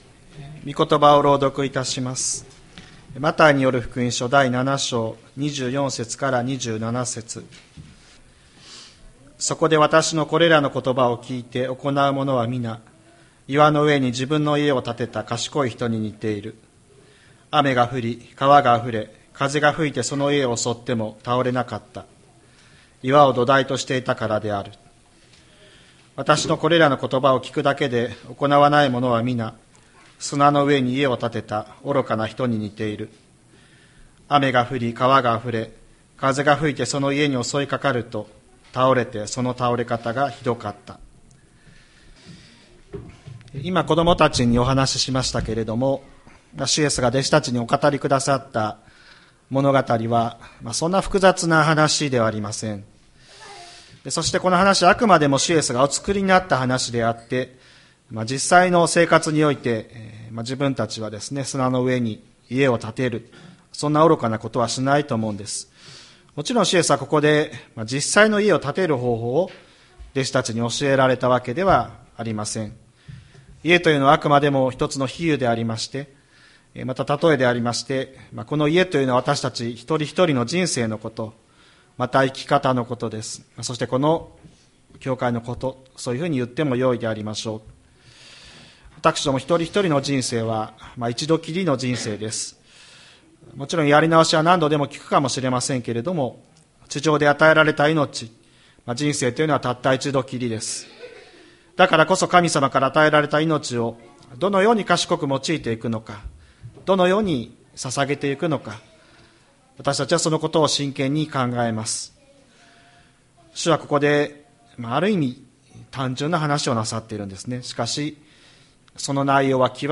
千里山教会 2023年11月26日の礼拝メッセージ。